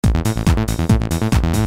Qui potrete trovare files in formato .rbs e .wav da usare in modo loop, per poterli edittare, trasformare, oppure elaborare in sequenza con programmi appropriati, che sicuramente, gli appassionati a questo tipo di lavoro conoscono molto bene.
Sequence mp3